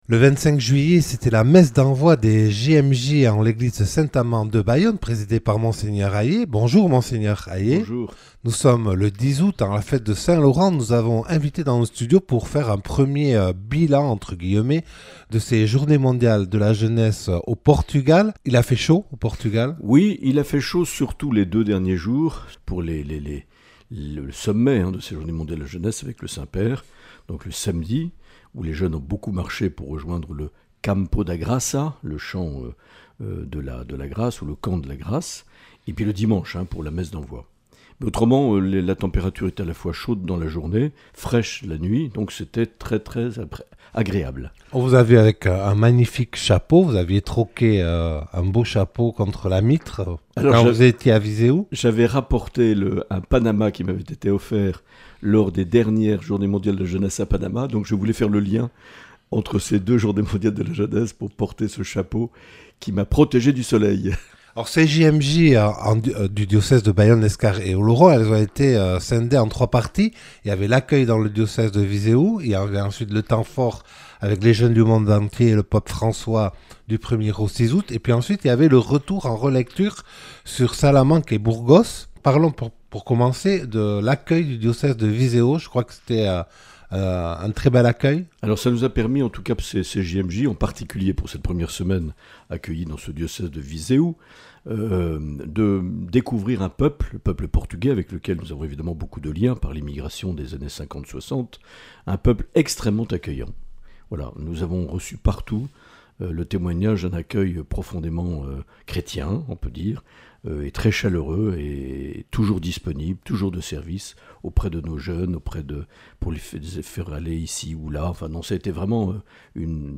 Mgr Marc Aillet nous dit comment il a vécu ces Journées Mondiales de la Jeunesse de Lisbonne lors d’un entretien enregistré le 10 août 2023.